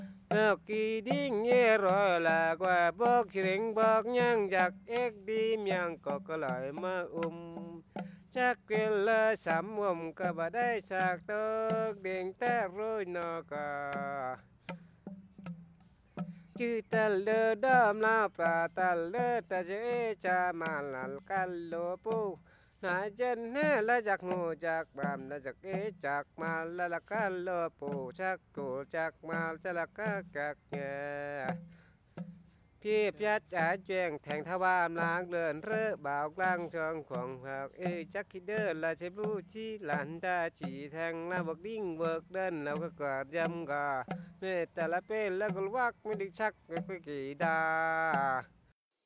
song to welcome guests the singer is accompanied by bamboo sticks
The songs I have heard have a strong rapid rhythm and are reminiscent of the music of the Issaan or Laos.
Track 20 Mlabri song.mp3